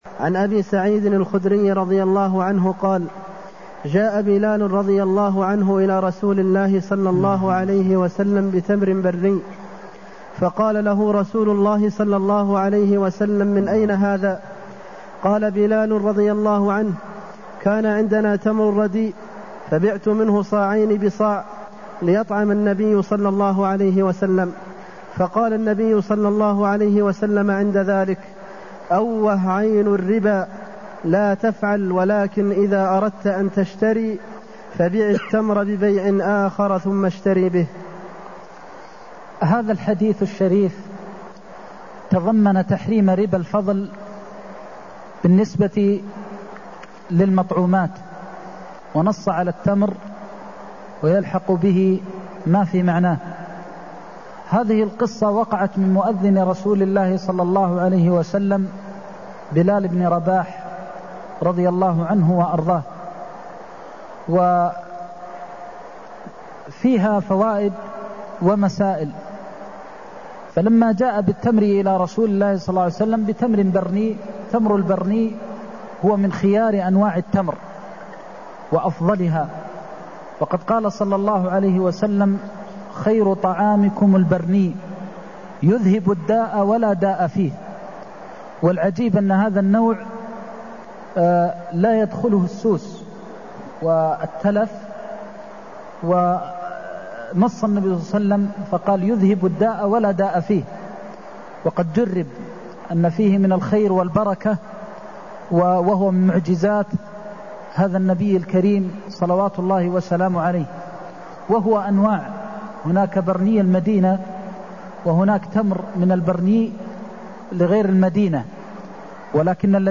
المكان: المسجد النبوي الشيخ: فضيلة الشيخ د. محمد بن محمد المختار فضيلة الشيخ د. محمد بن محمد المختار بع التمر ببيع آخر ثم اشتر به (262)